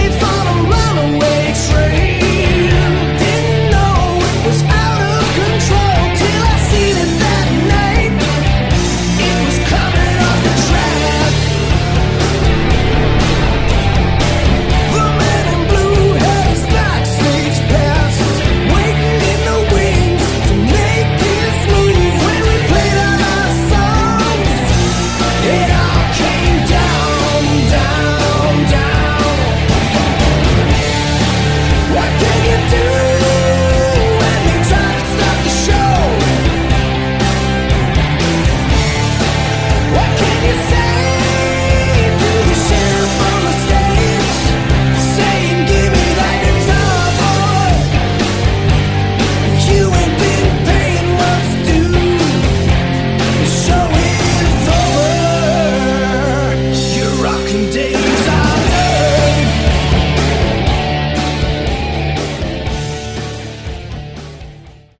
Category: Hard Rock
Vocals, Additional Drums
Guitars
Bass
Drums